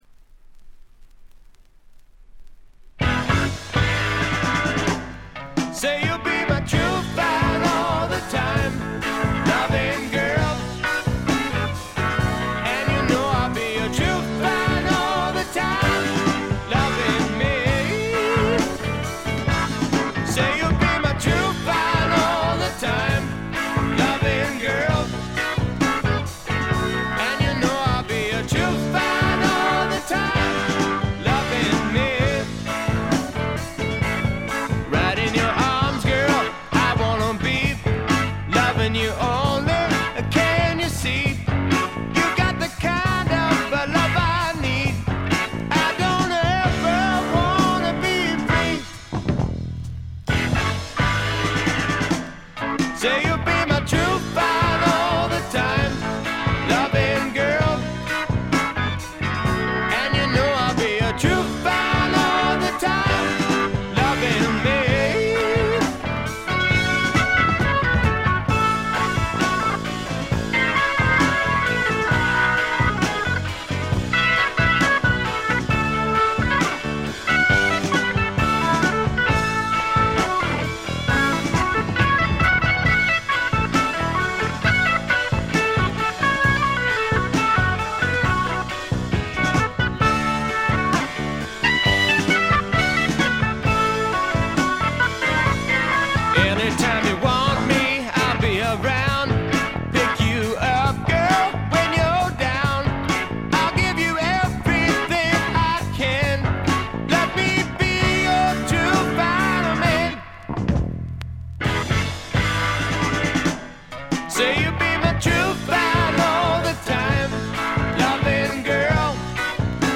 静音部での軽微なチリプチ程度。
試聴曲は現品からの取り込み音源です。